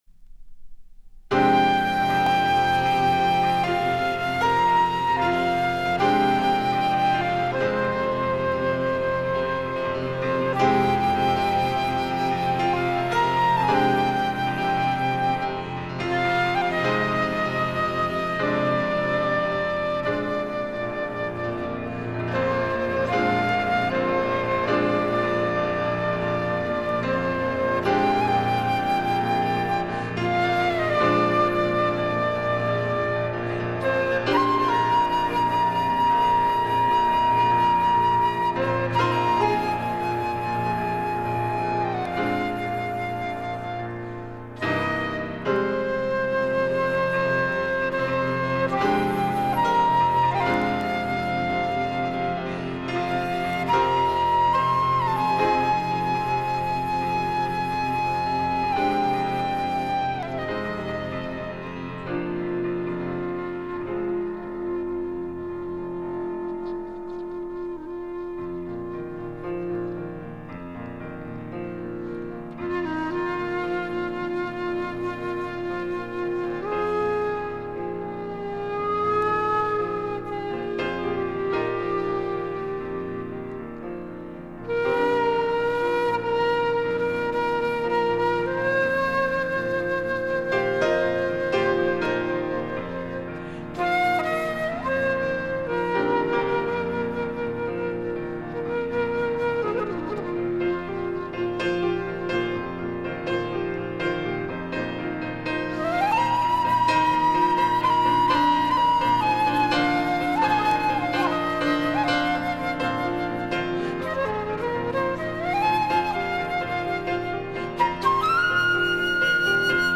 Flûte, piano / flûte solo